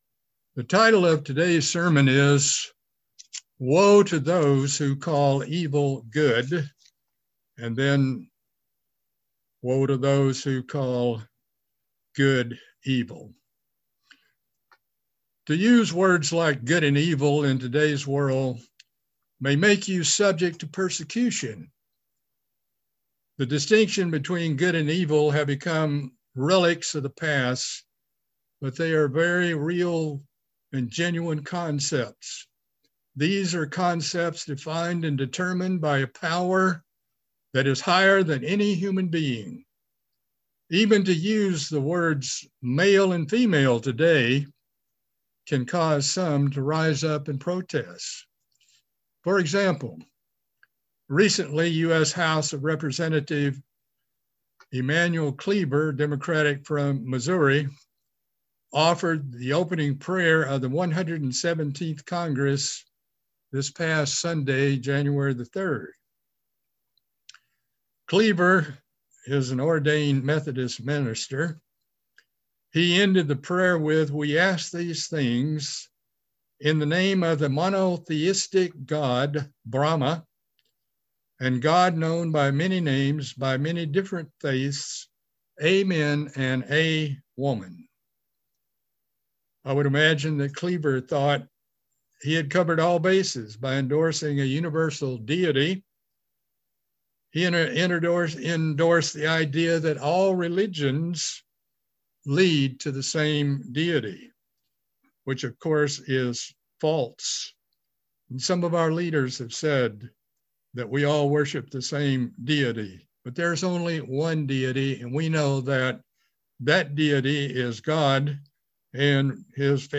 Woe to Those Who Call Evil Good | United Church of God